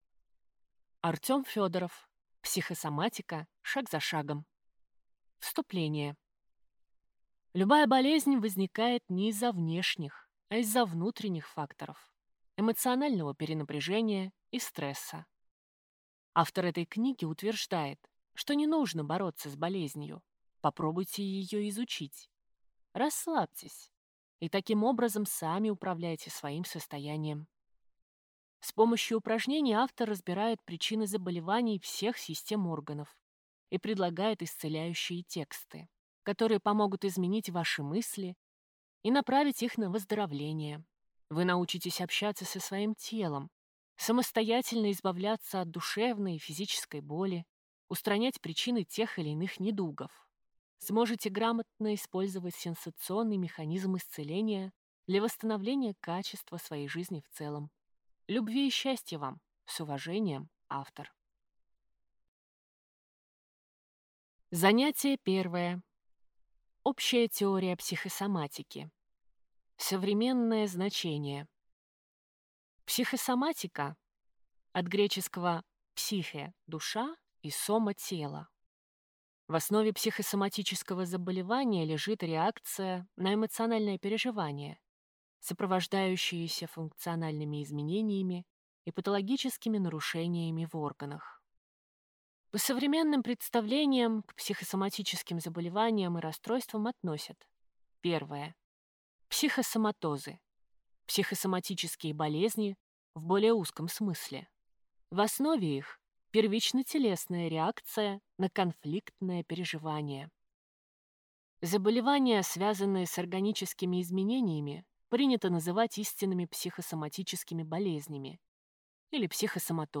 Аудиокнига Психосоматика шаг за шагом | Библиотека аудиокниг